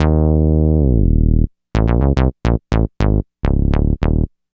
Bass 46.wav